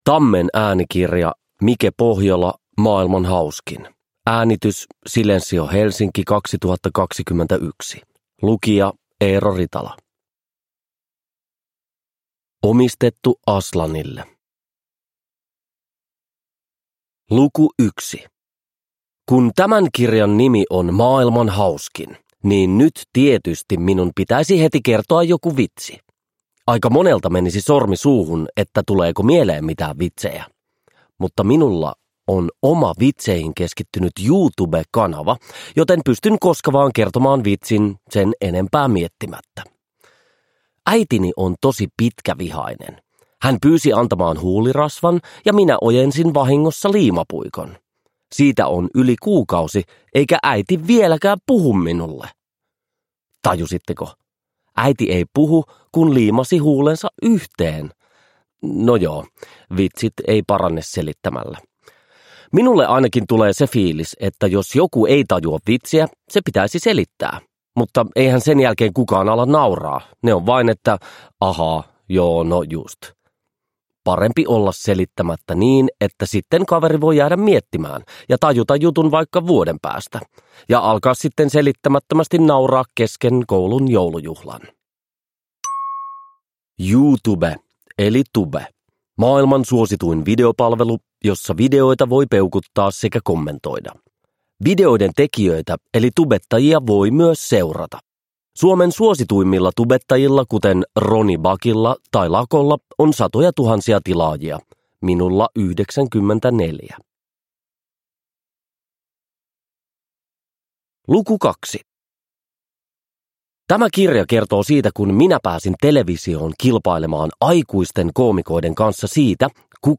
Maailman hauskin – Ljudbok – Laddas ner